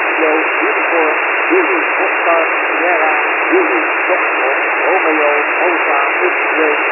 The spelling words are per word adjusted to all having the same PEP
Random sequences of 10 characters are generated in white noise, band
( 6.0 S/N ratio)
Each file contains 10 random spelled out characters.